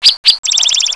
Spotted Towhee spotted towhee
Their song is a long buzzy chweeee.
Other sounds are shenk, chup-chup zedededee, and a cat-like meew call.
Spottedtowhee.wav